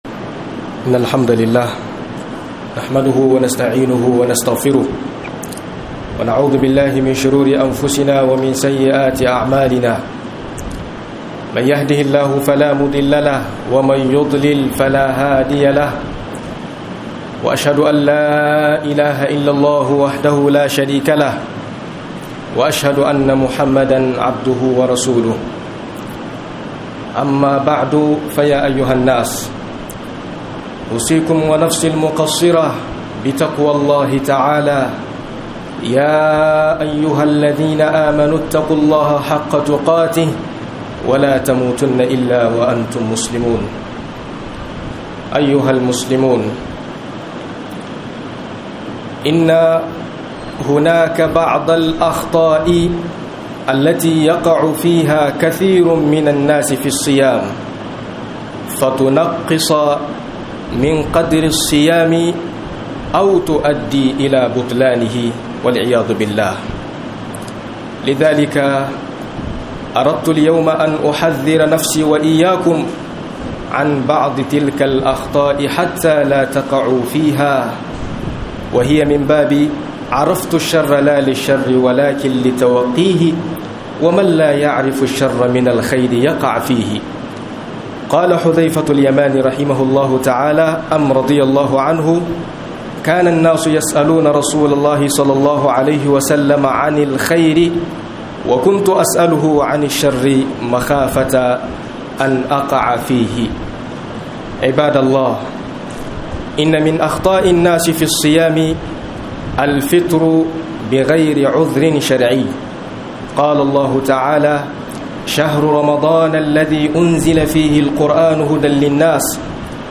04 Kurkuran Mutane a Azumi - MUHADARA